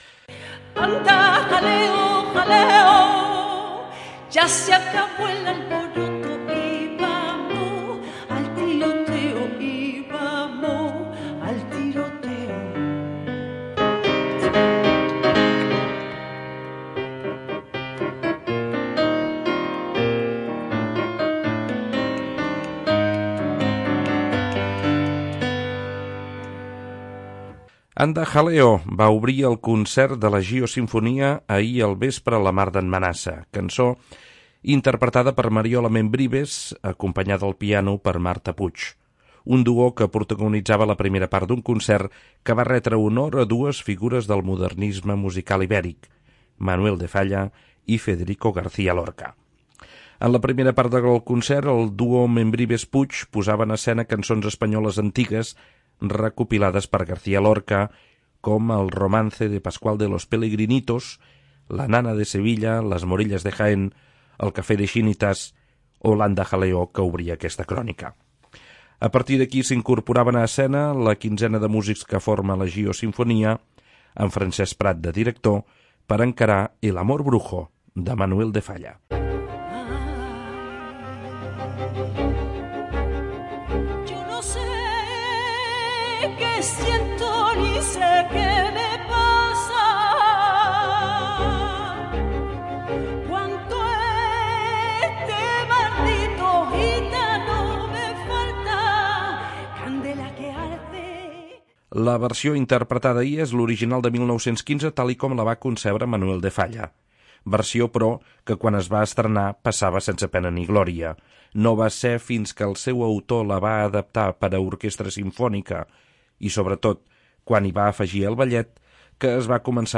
amb una veu potent i brillant
piano